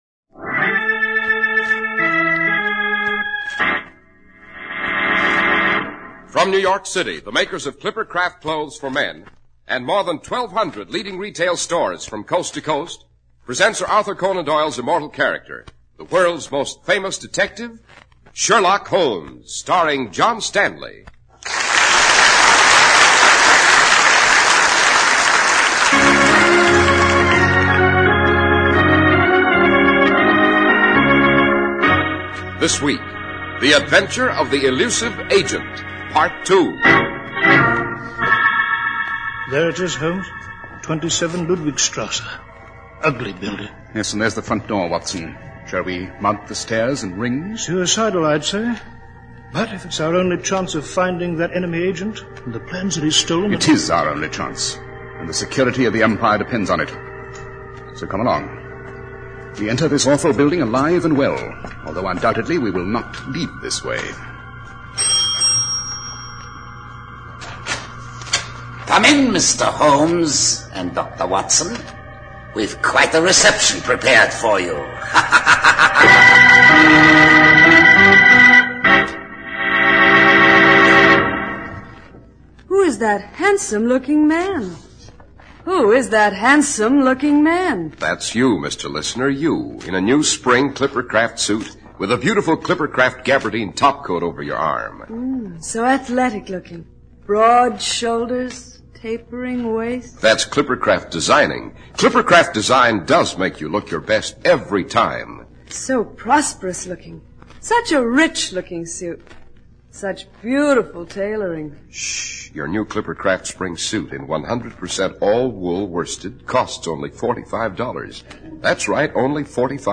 Radio Show Drama with Sherlock Holmes - The Elusive Agent 2 1949